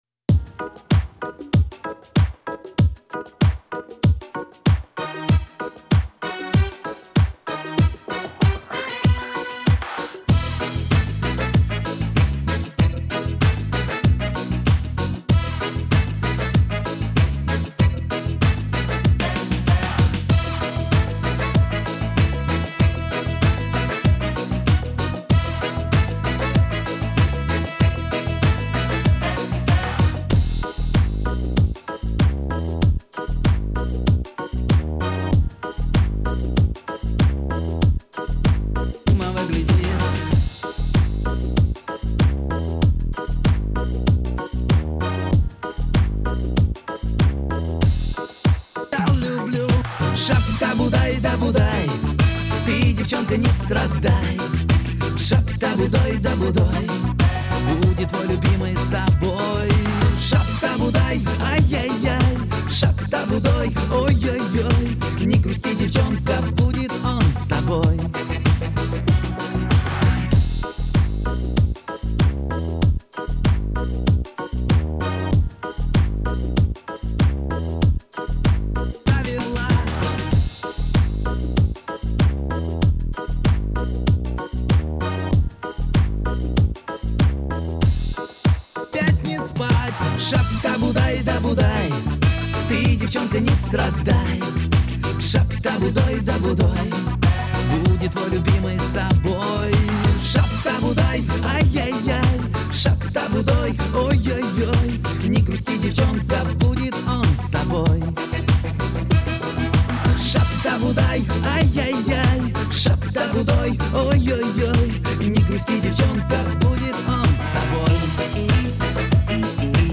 минусовка версия 29382